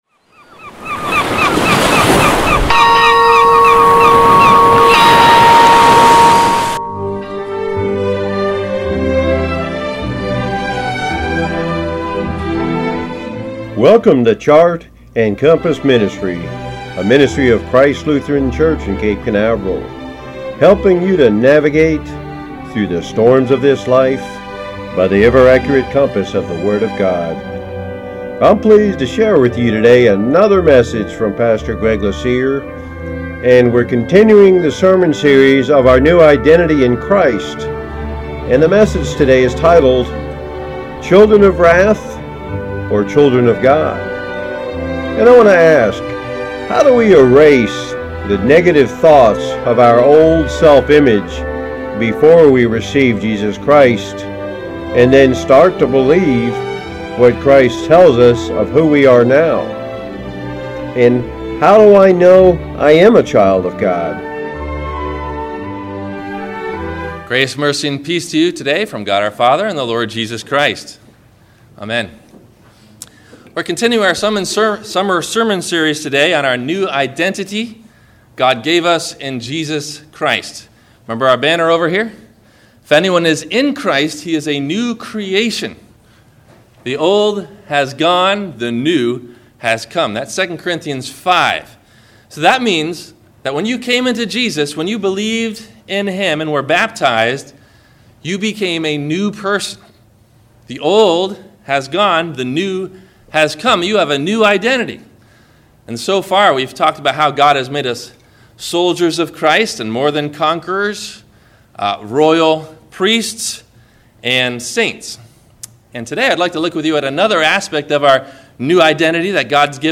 Children of Wrath or Children of God? – WMIE Radio Sermon – September 04 2017